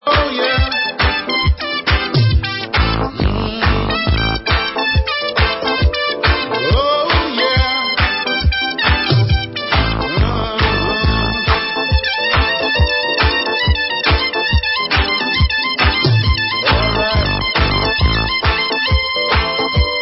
World/Reggae